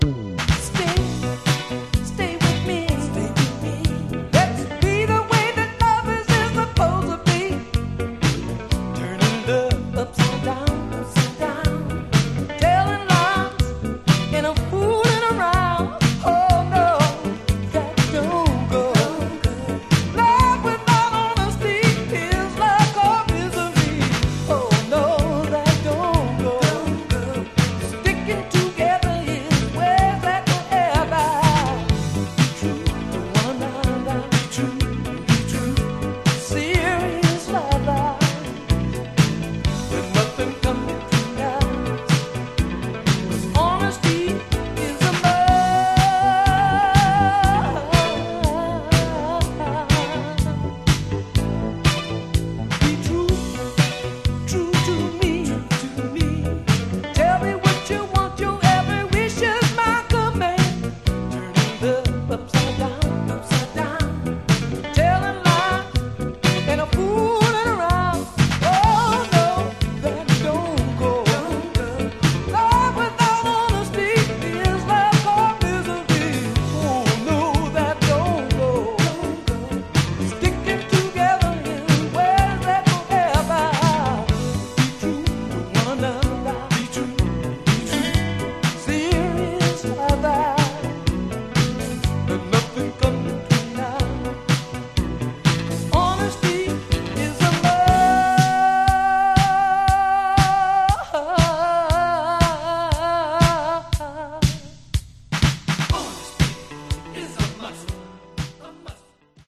Genre: Funky Soul